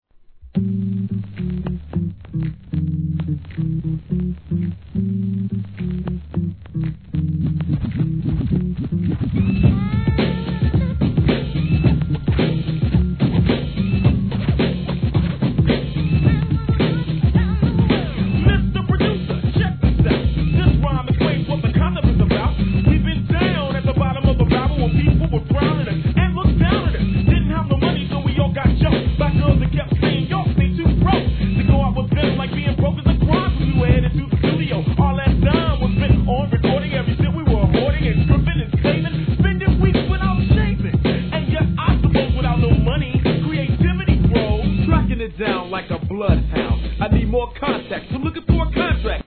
1. HIP HOP/R&B
1993年アンダーグランド!!渋いネタ使いに個性派のMICリレーは当初から根強い人気!!